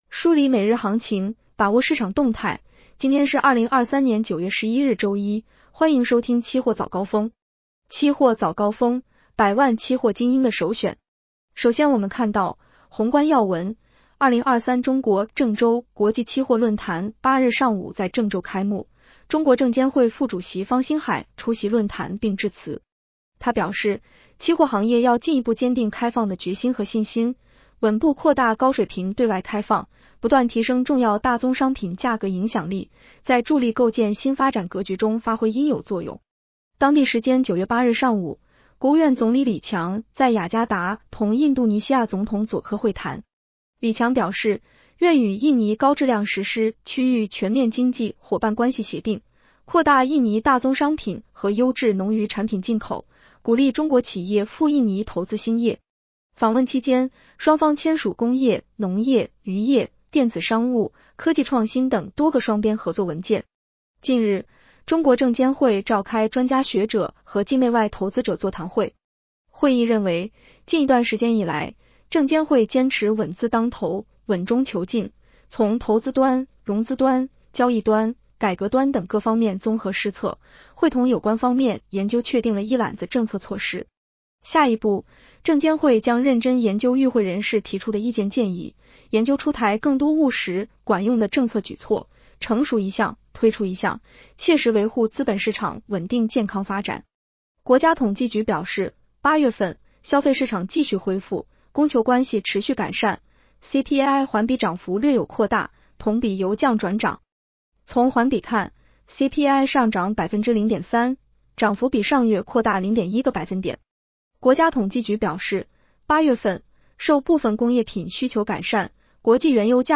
【期货早高峰-音频版】 女声普通话版 下载mp3 宏观要闻 1. 2023中国（郑州）国际期货论坛8日上午在郑州开幕，中国证监会副主席方星海出席论坛并致辞。